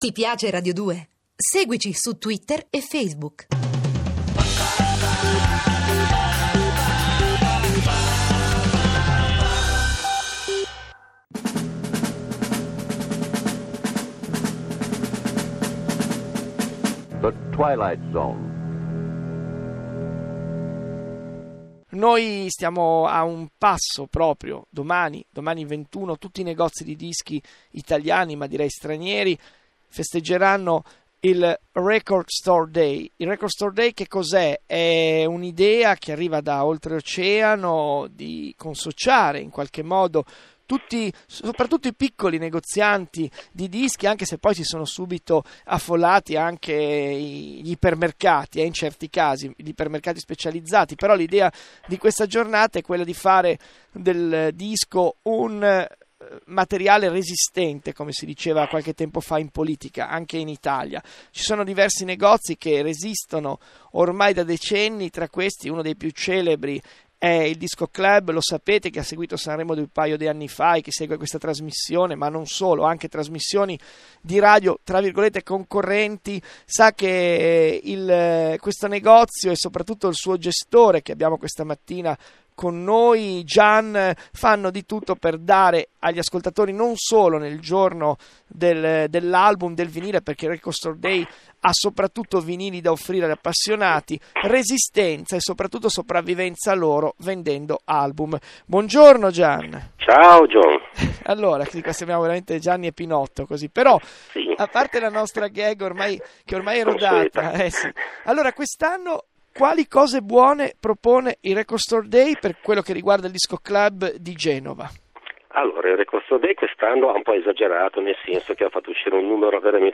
intervista.mp3